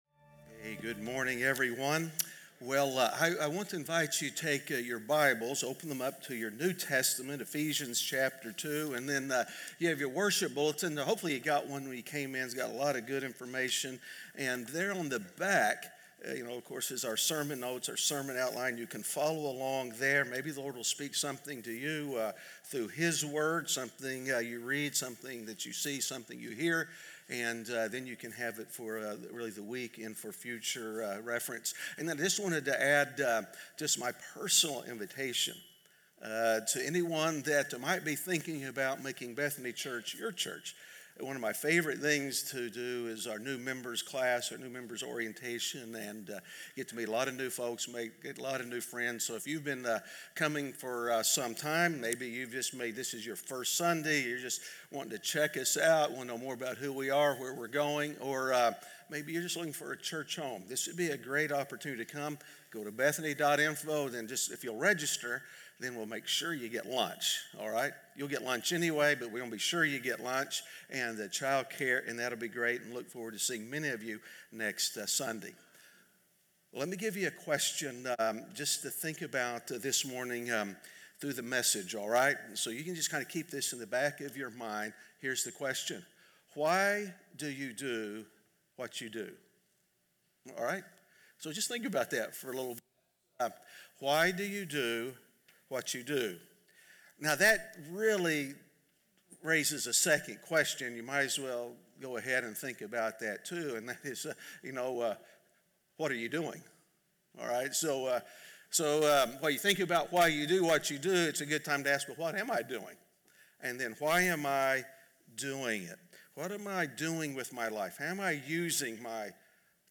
A message from the series "The Walk."